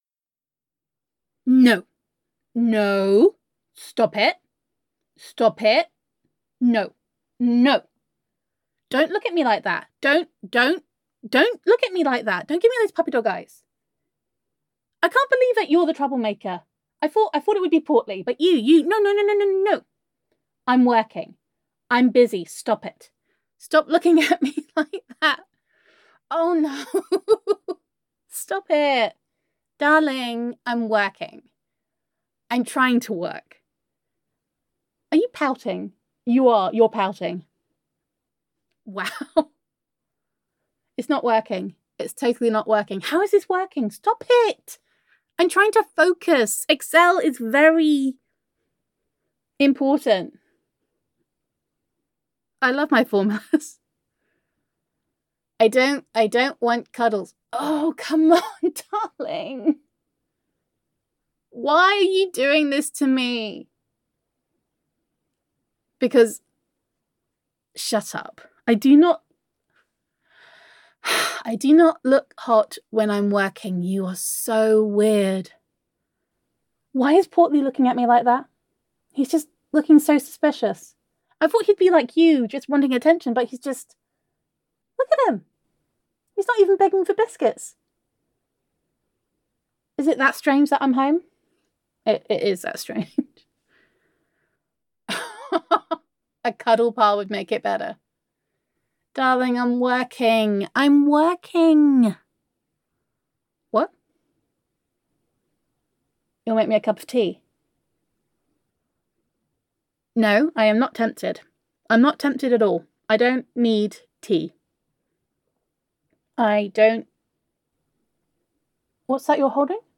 [F4A] Day One - Break Time [Girlfriend Roleplay][Self Quarantine][Domestic Bliss][Gender Neutral][Self-Quarantine With Honey]